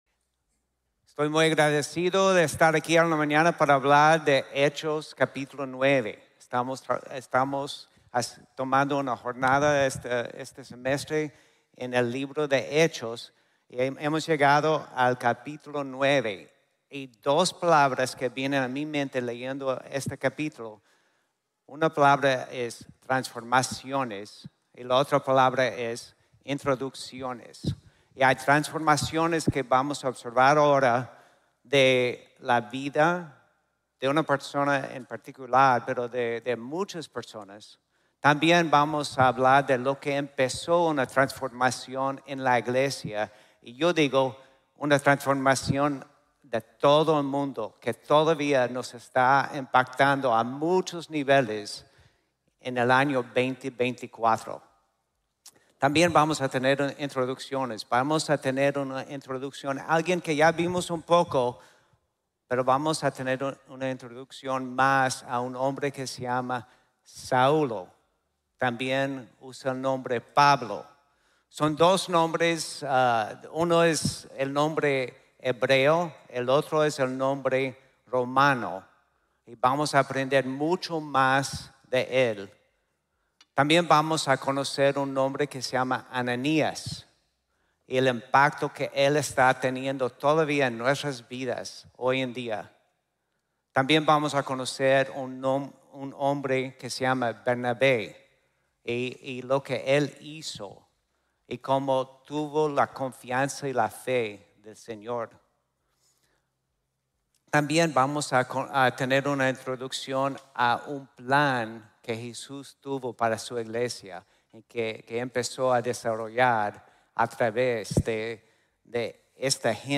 Transformaciones e Introducciones | Sermon | Grace Bible Church